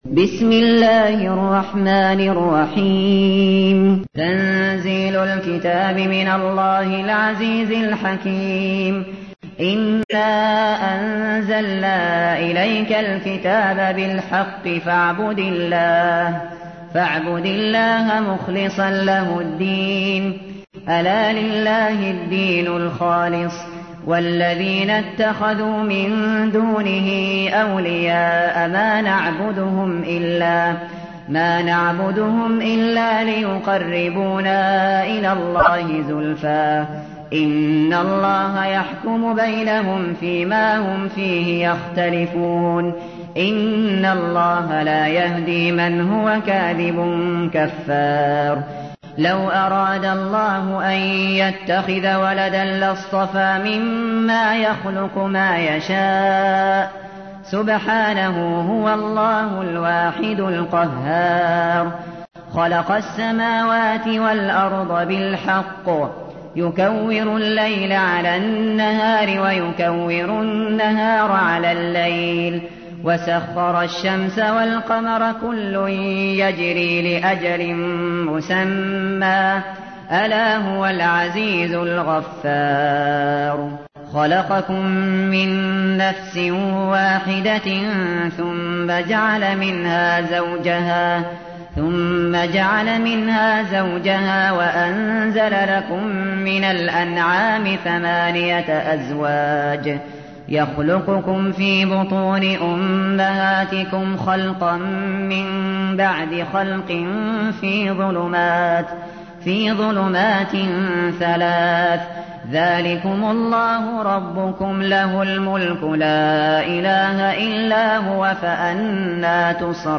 تحميل : 39. سورة الزمر / القارئ الشاطري / القرآن الكريم / موقع يا حسين